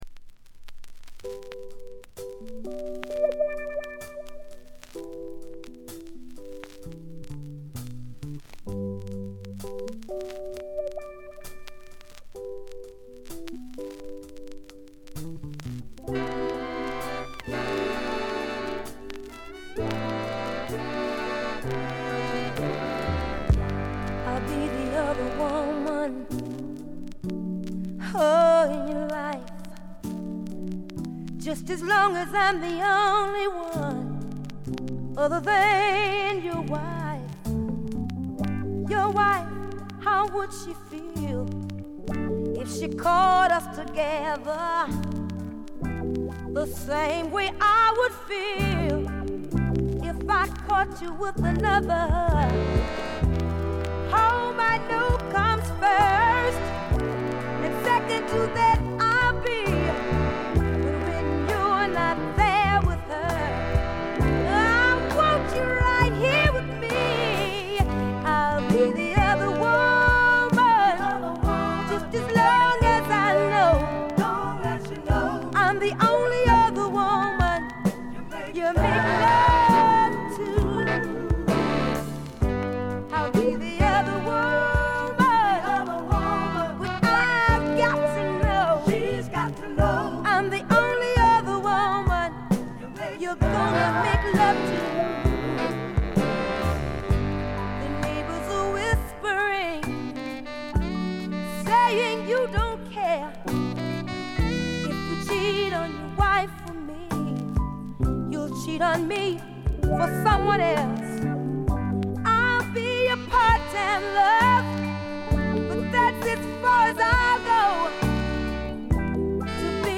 バックグラウンドノイズ、チリプチ多め大きめです（特にA面）。
メンフィス産の男女4人組のソウル・ヴォーカル・グループ。
栄光の60年代スタックス・ソウルの正統なる継承者とも言えるディープなヴォーカルと適度なメロウさがたまらないです。
試聴曲は現品からの取り込み音源です。